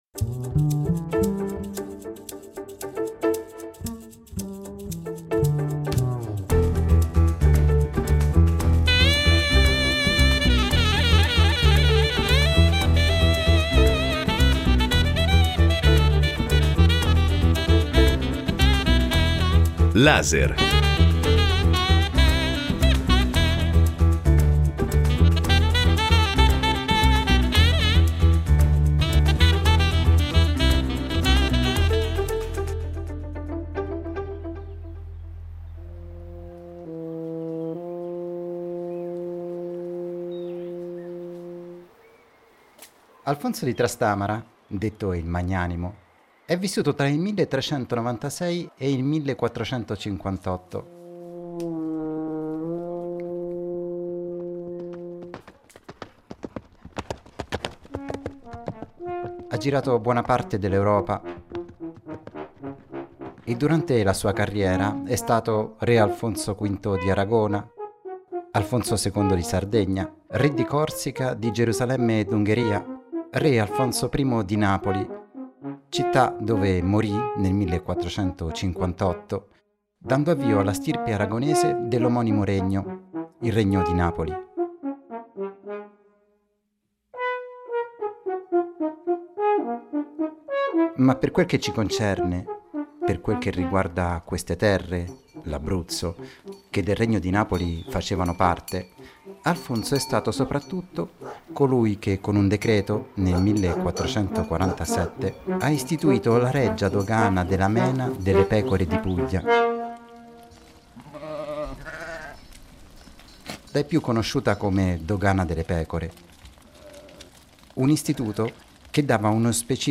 La rete dei tratturi era un sistema viario imponente: superava i 3100 km, attraversava 3 regioni e si componeva di 3 arterie maggiori, i tratturi magni, e un centinaio di vie secondarie. Passo di pecora è il racconto di una transumanza ed è stato realizzato durante una delle transumanze organizzate ad Anversa degli Abruzzi dalla cooperativa Asca e La Porta dei Parchi .